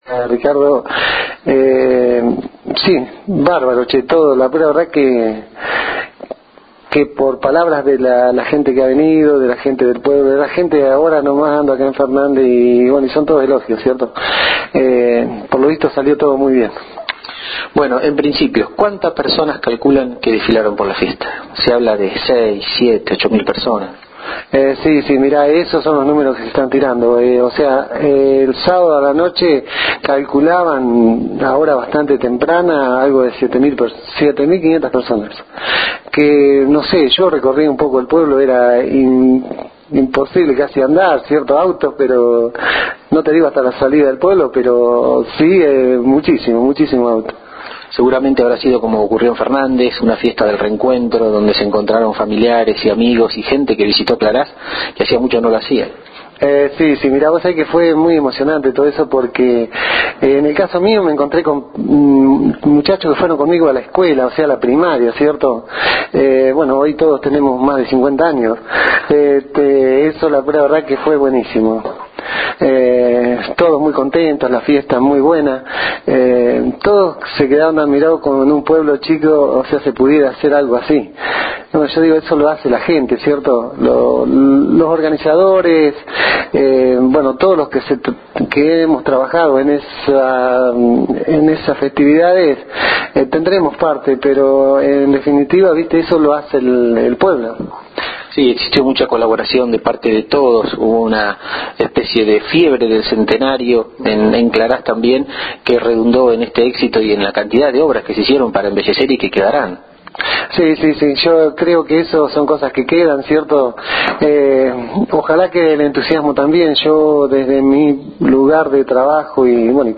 El lunes 16, por la mañana, día del Centenario de Claraz conversamos con quien es el tercer delegado Municipal de la dinastía Aineseder y cuarta o quinta generación desde la fundación a la fecha manteniendo el apellido en esta localidad.